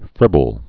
(frĭbəl)